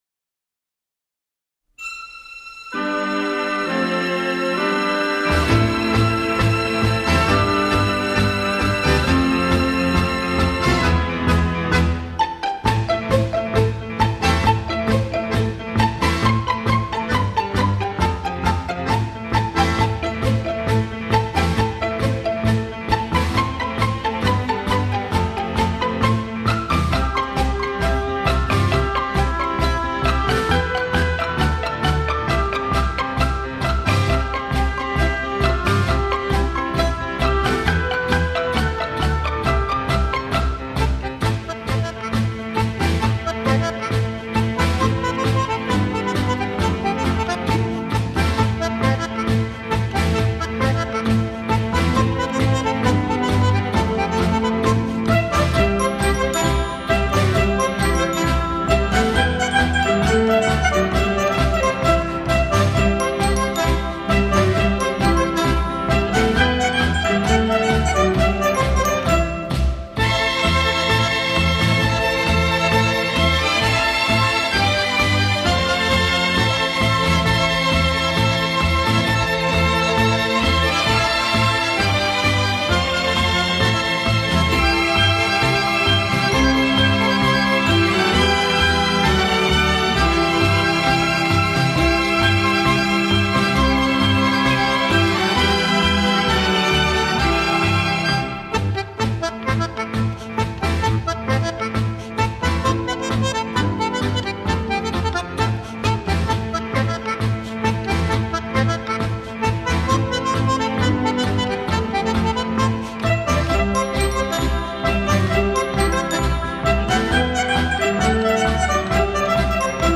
Ballroom Dance